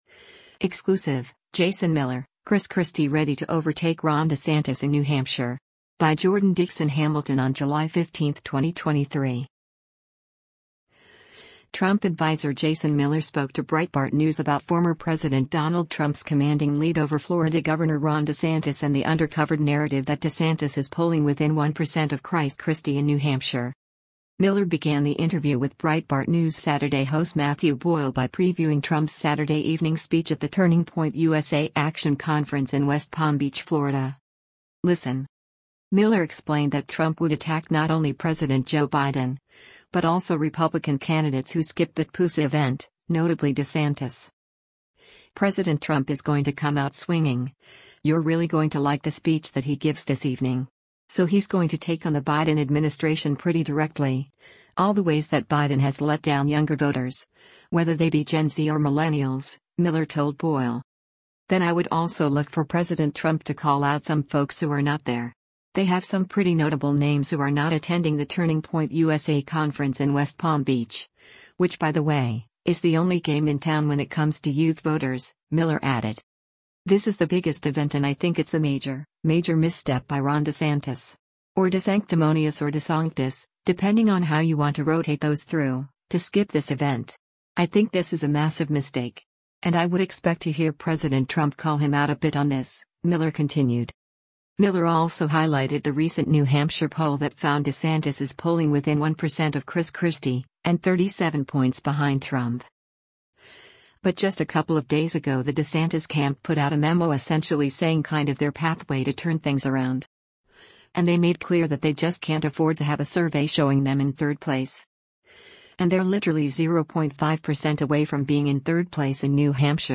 Trump adviser Jason Miller spoke to Breitbart News about former President Donald Trump’s commanding lead over Florida Gov. Ron DeSantis and the “under covered” narrative that DeSantis is polling within one percent of Christ Christie in New Hampshire.
Breitbart News Saturday airs on SiriusXM Patriot 125 from 10:00 a.m. to 1:00 p.m. Eastern.